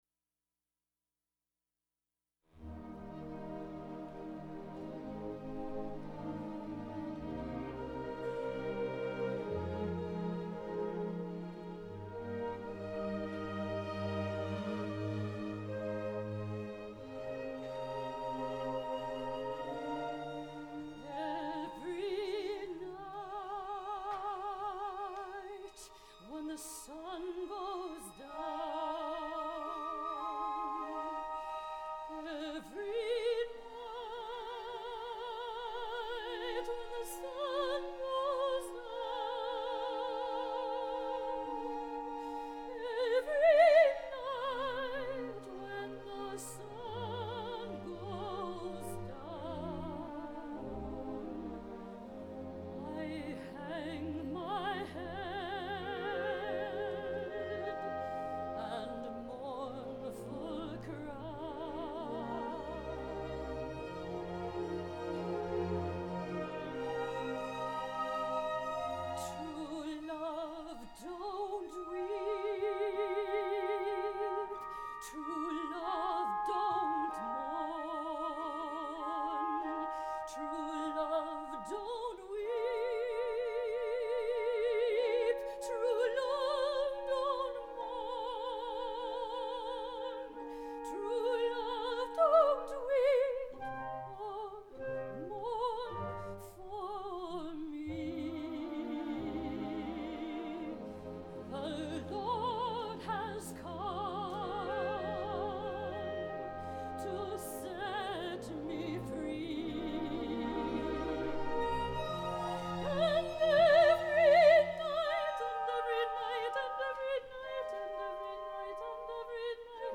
for Soprano and Orchestra (2010)
The voice and accompaniment ascend and fade away.